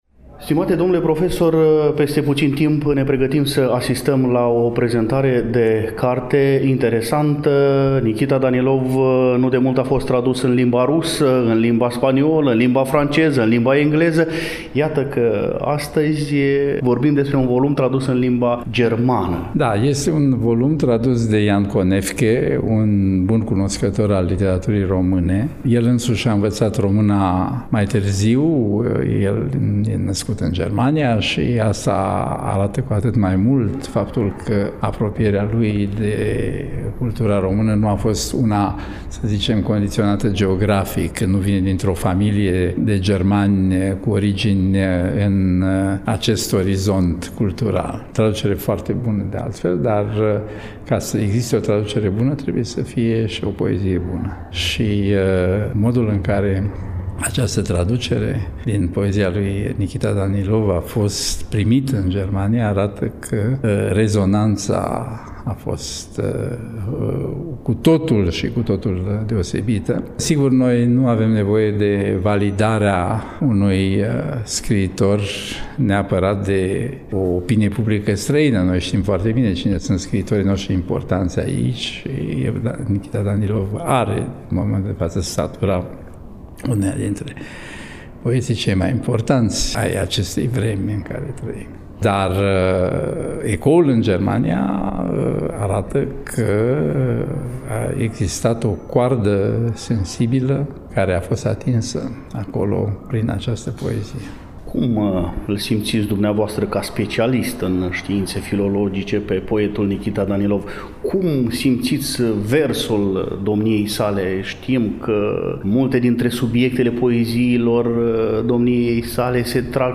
Despre toate acestea, dar și despre motivele suprinse în versurile din volumul Vulturii orbi/ Die blinden Adler aflăm amănunte din următorul dialog.